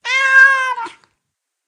PixelPerfectionCE/assets/minecraft/sounds/mob/cat/meow3.ogg at ca8d4aeecf25d6a4cc299228cb4a1ef6ff41196e
meow3.ogg